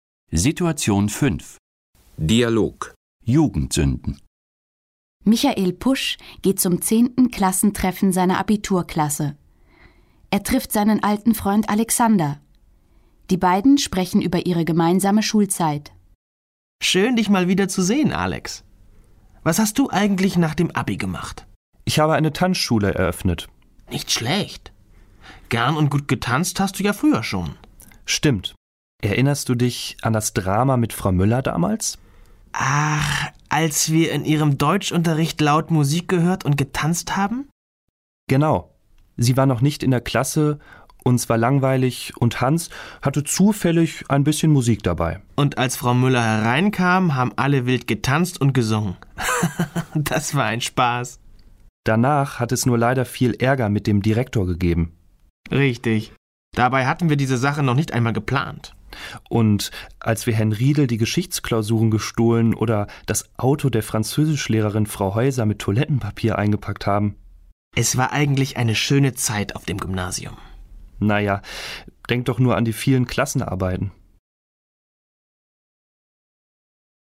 Situation 5 – Dialog: Jugendsünden (1235.0K)